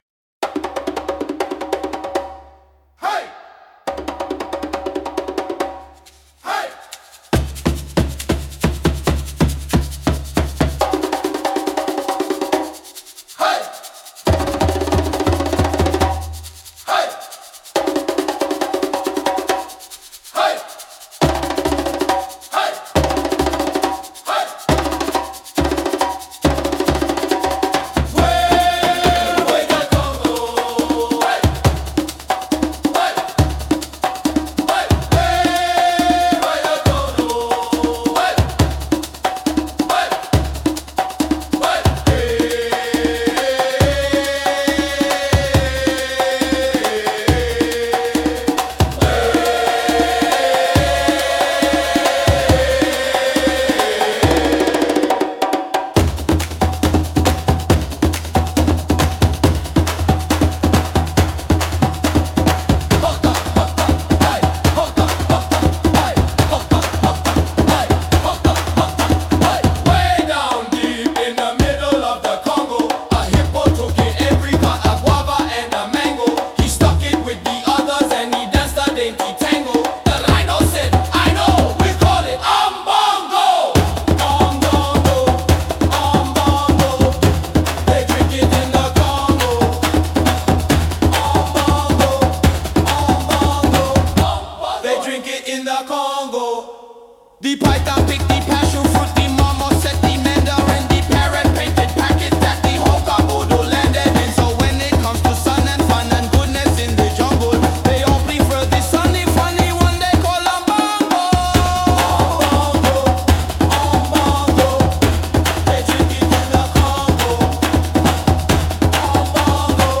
A lengthy, slower version